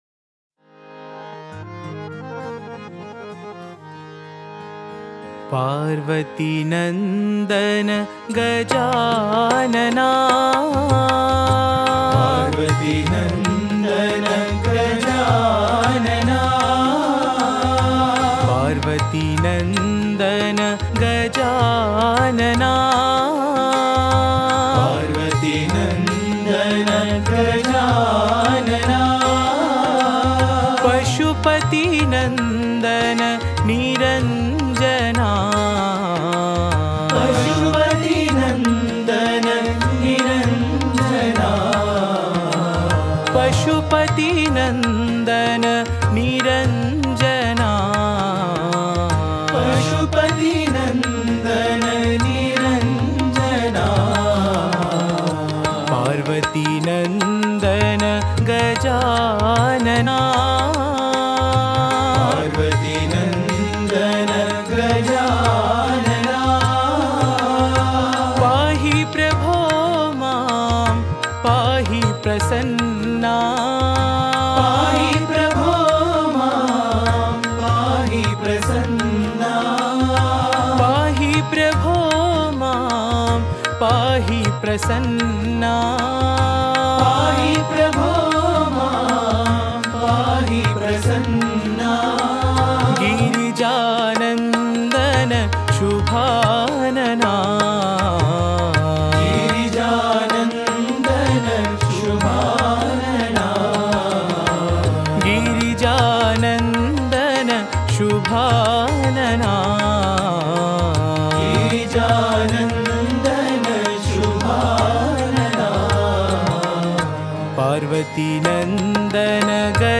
Author adminPosted on Categories Ganesh Bhajans